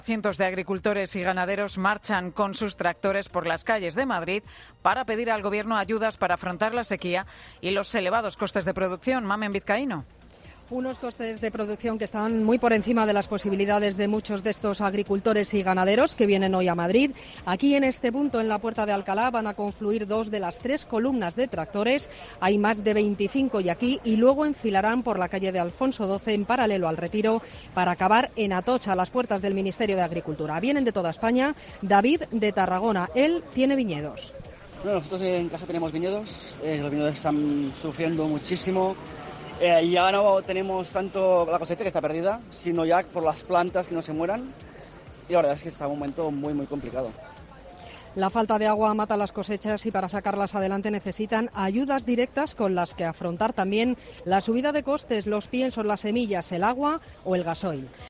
sobre la tractorada en Madrid